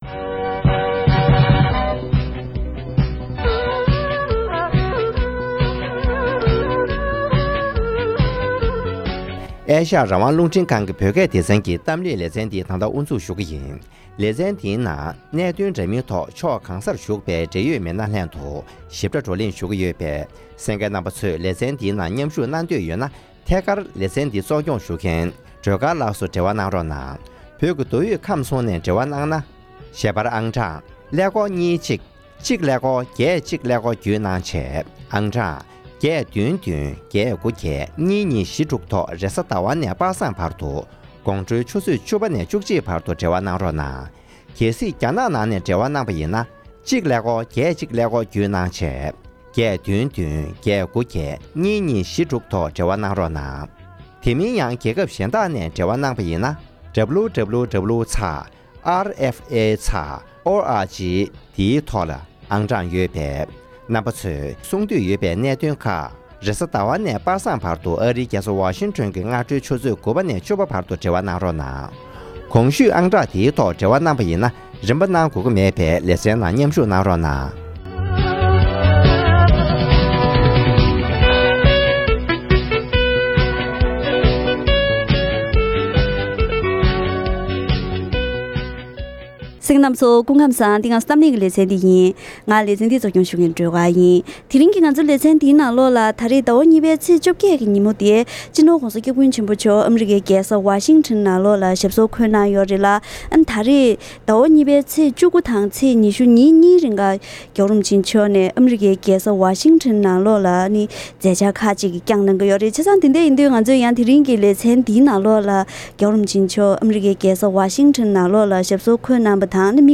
༄༅། །དེ་རིང་གི་གཏམ་གླེང་ལེ་ཚན་འདིའི་ནང་།